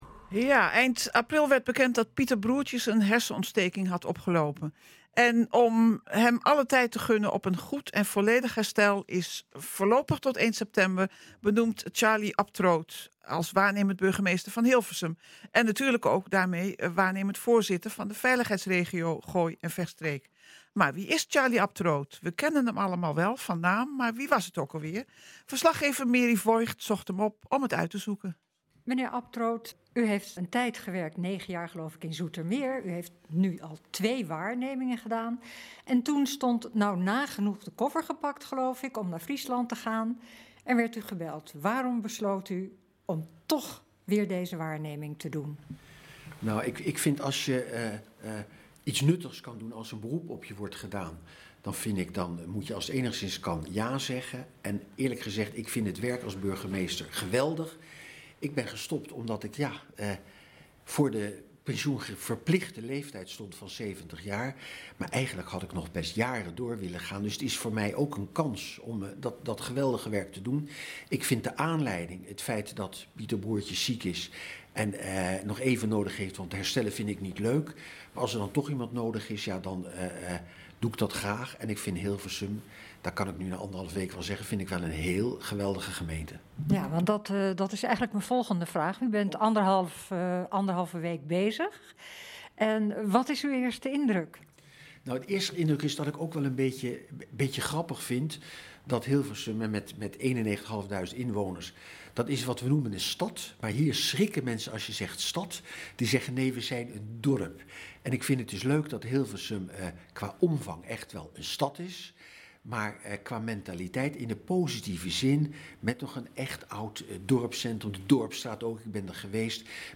NH Gooi Zaterdag - Reportage kennismakingsgesprek waarnemend burgemeester H'sum Aptroot
nh-gooi-zaterdag-reportage-kennismakingsgesprek-waarnemend-burgemeester-aptroot.mp3